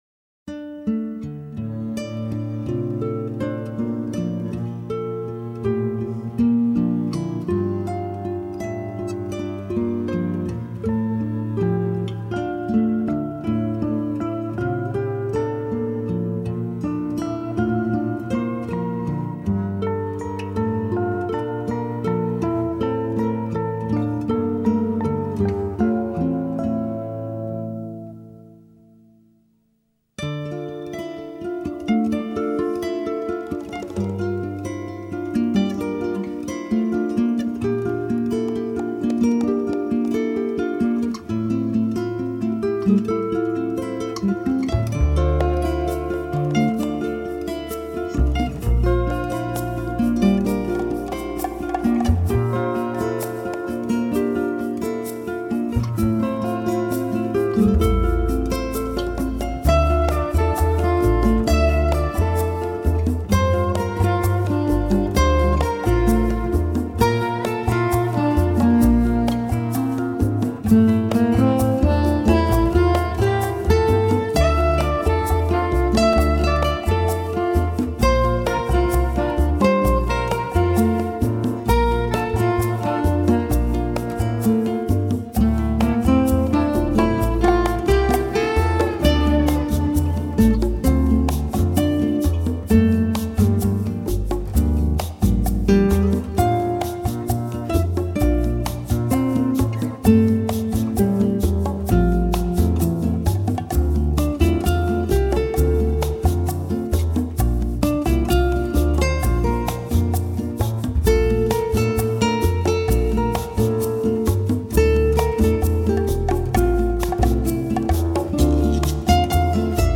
柔和的爵士乐、幽暗的咖啡厅，我回忆起过往生活的点滴，恋人的、母亲的、朋友的……
融合夫吕号、小号、萨克斯、键盘、外加电子钢琴和哈蒙电子风琴等乐器。
且旋律收放自如，十分悦耳动人，是颇具流行音乐和爵士乐风格的音乐专辑。
强烈的感染力使人流连忘返，尽显吉他和弦无穷魅力。